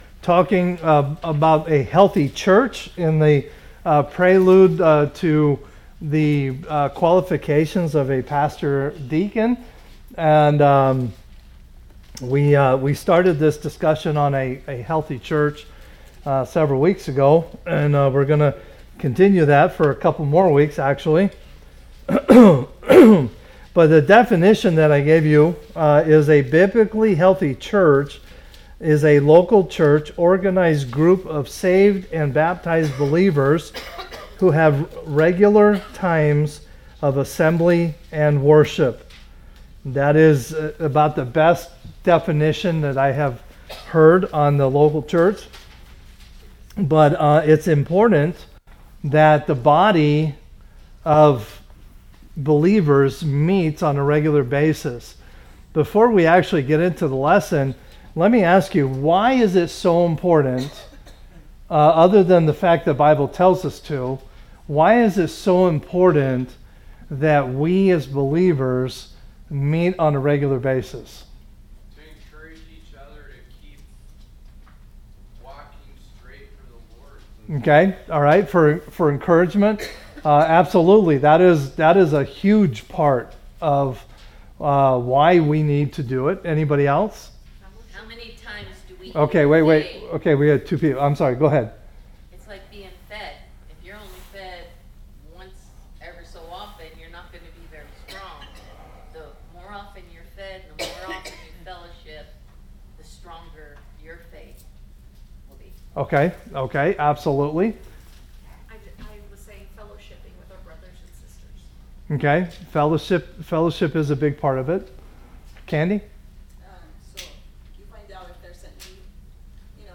A message from the series "General Series."